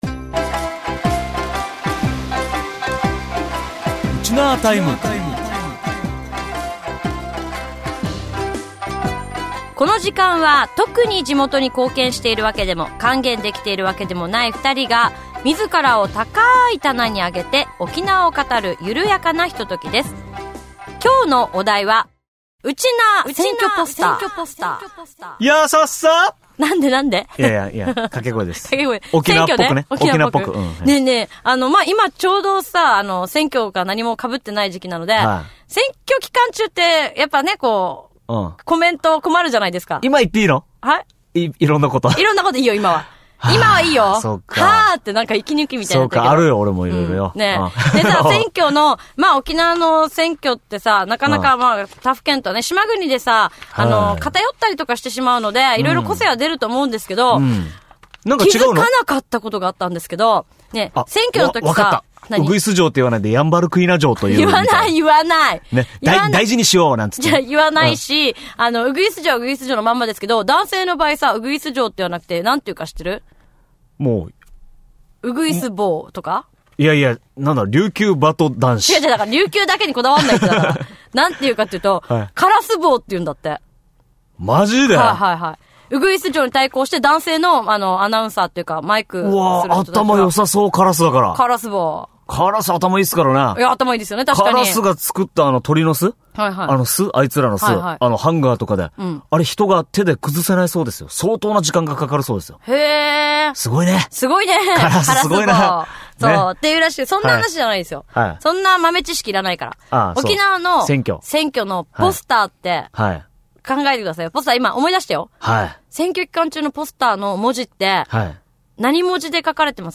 地元沖縄トーク♪